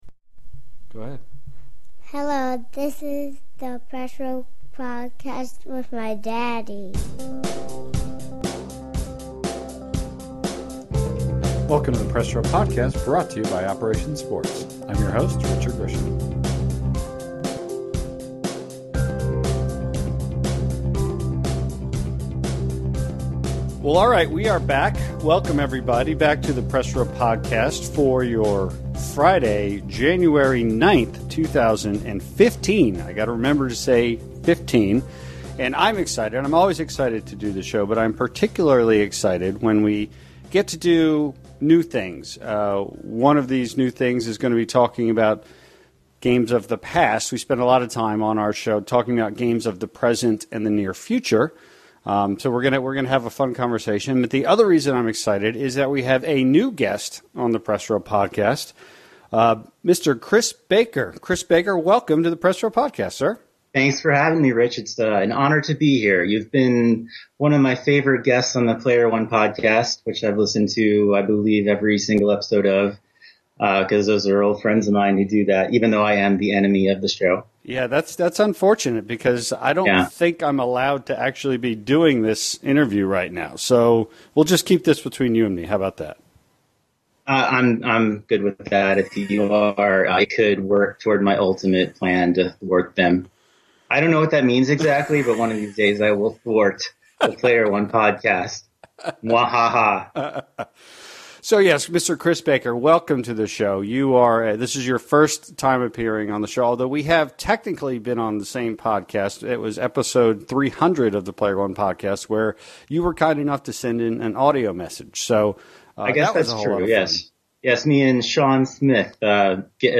In addition, the two discuss other interesting and honorable mentions as well as listing a bunch of listener-submitted nominations.